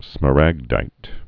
(smə-răgdīt)